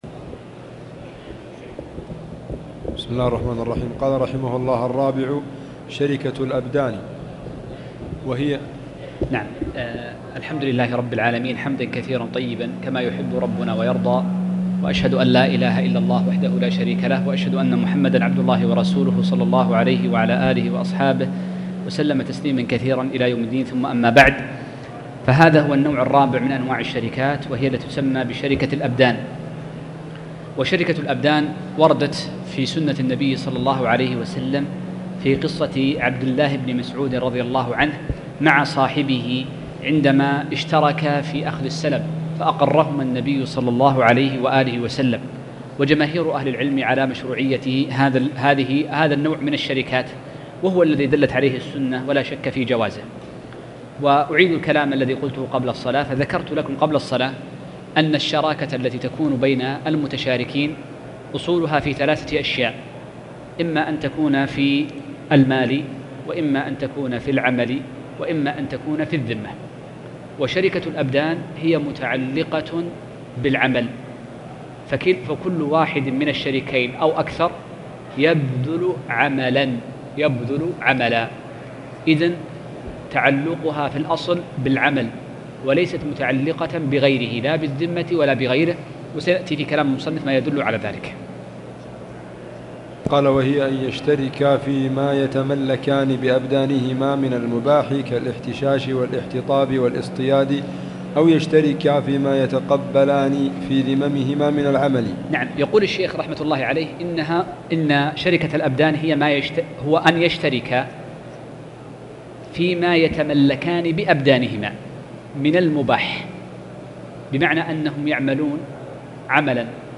تاريخ النشر ١٨ جمادى الآخرة ١٤٣٨ هـ المكان: المسجد الحرام الشيخ